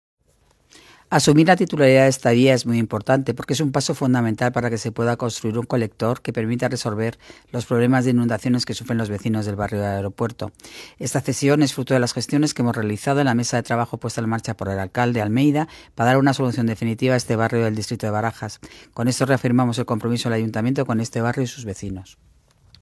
Nueva ventana:Intervención de la delegada de Obras y Equipamientos, Paloma García Romero, en la firma de hoy: